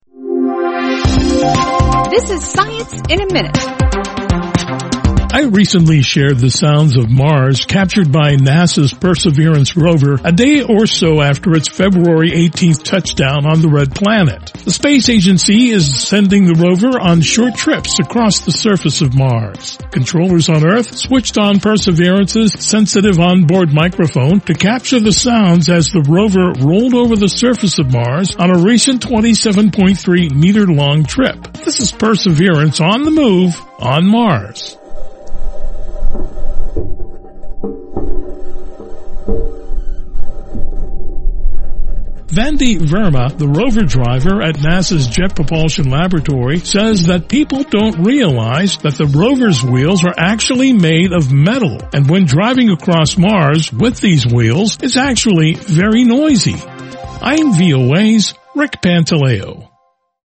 Sounds of Perseverance Moving Across Mars
NASA’s new Perseverance Rover is on the move. On a recent 27.3 meter trip over the surface of the Red Planet, controllers at the Jet Propulsion Laboratory switched on the rover’s sensitive microphone to capture the sounds it made as it moved across Mars.